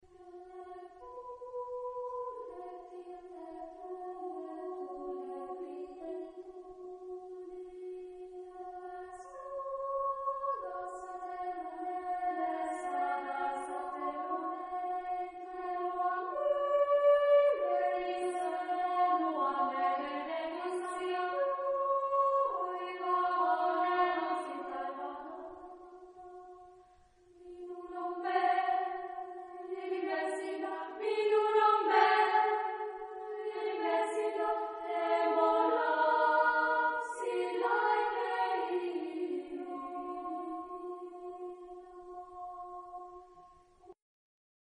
Género/Estilo/Forma: Popular ; Profano
Carácter de la pieza : angustiado ; histórico ; triste
Tipo de formación coral: SA  (2 voces Coro infantil )
Tonalidad : si menor